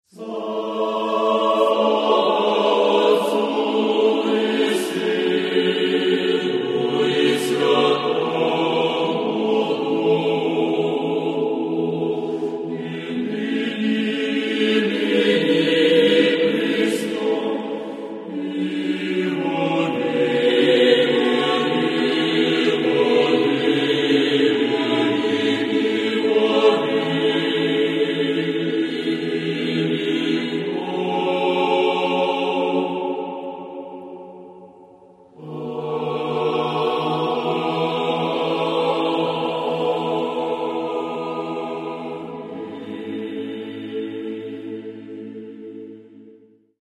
Церковная